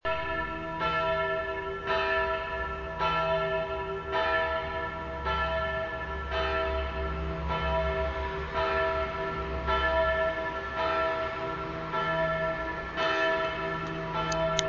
Mutter-Gottes-Glocke
Ton: E (
Gewicht: 1100 kg
pfarreien_gommiswald_unsereglocken_muttergottesgl.MP3